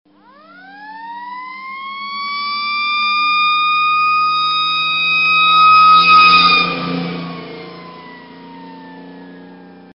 Звук удаляющейся американской пожарной машины с включенными сигналами